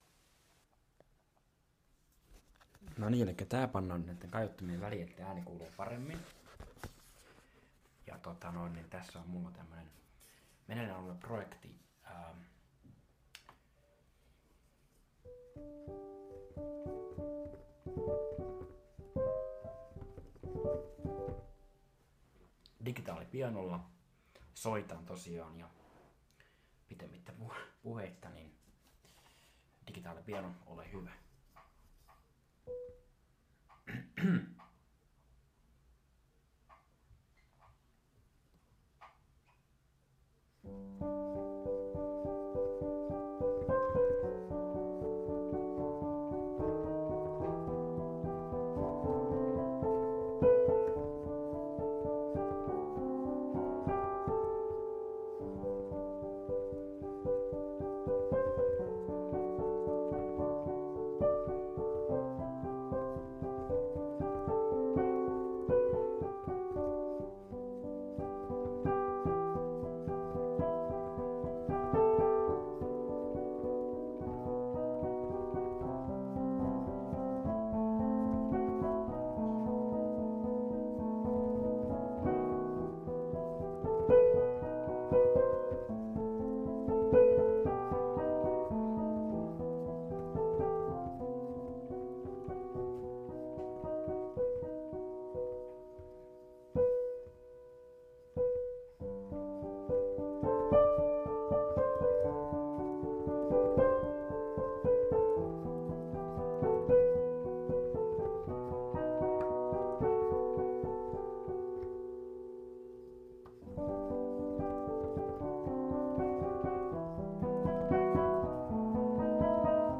Composition 1, for piano.
Me playing my new, probably to-be-published composition on the piano.
The talk in the beginning is Finnish, not of importance though.